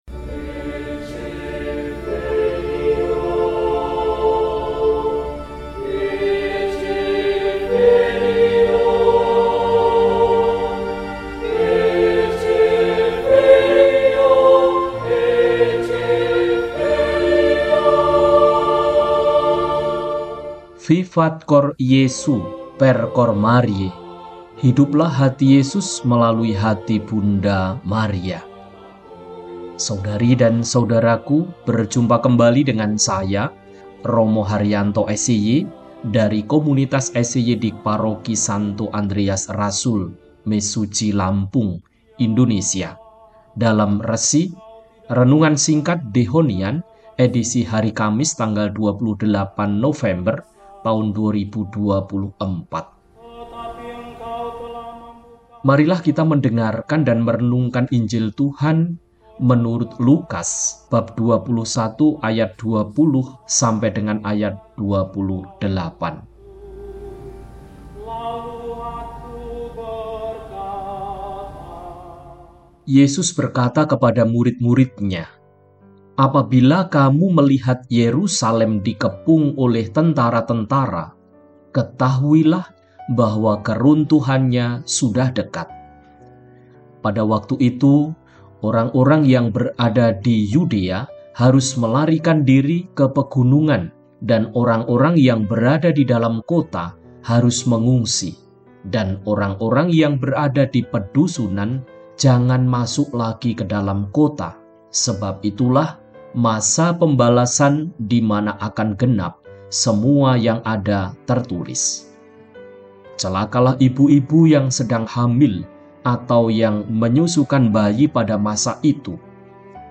Kamis, 28 November 2024 – Hari Biasa Pekan XXIV – RESI (Renungan Singkat) DEHONIAN